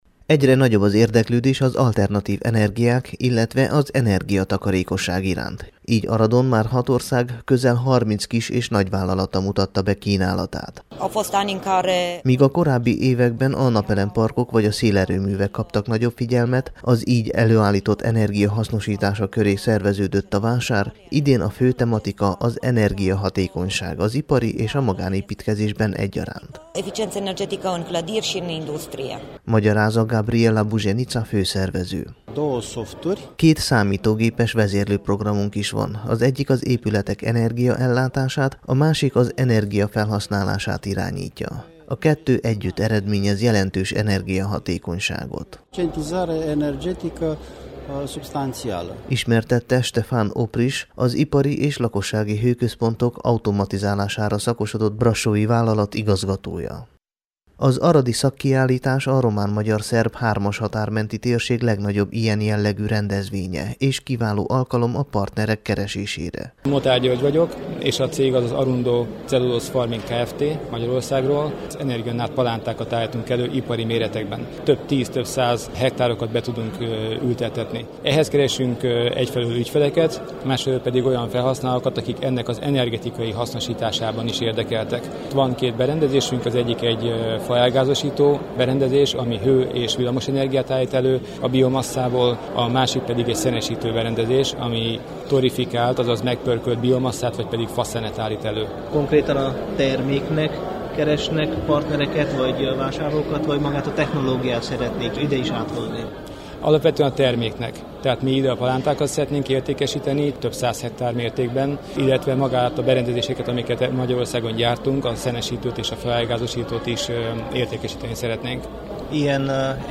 enreg_vasar_2015.mp3